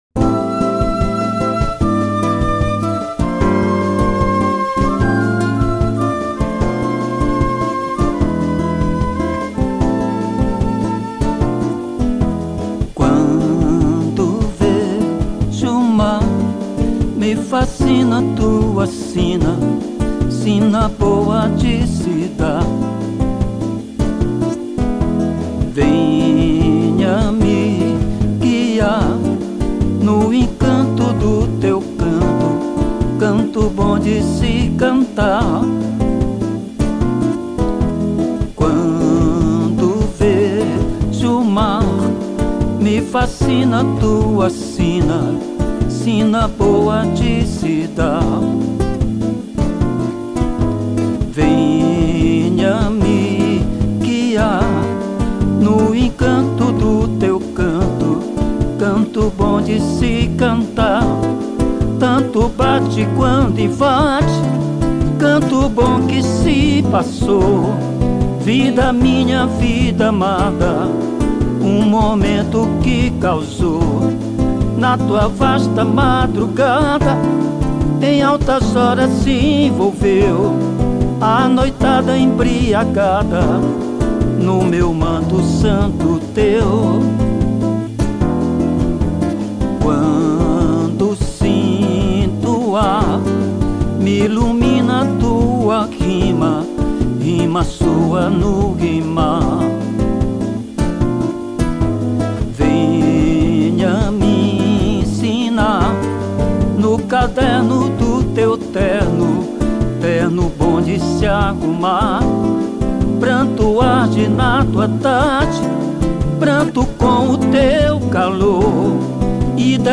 1229   05:24:00   Faixa:     Samba Canção
Guitarra, Violao Acústico 6